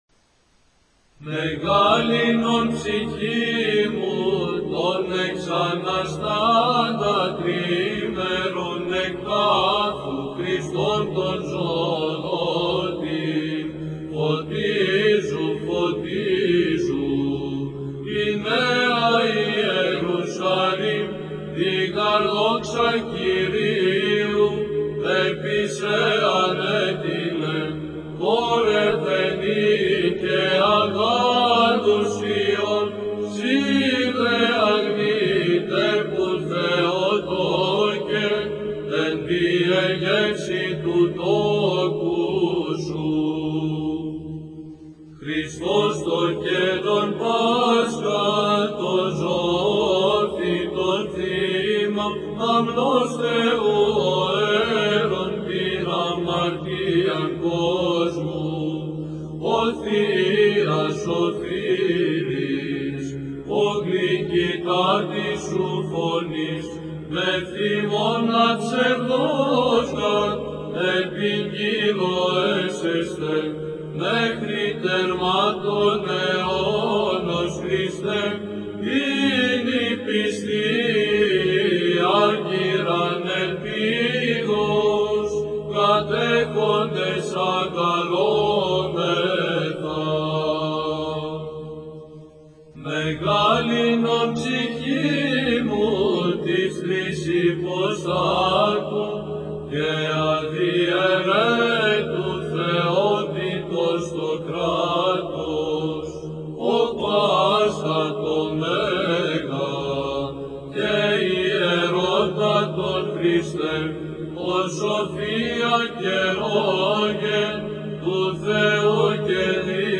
Hymns of the Holy Week
1st Mode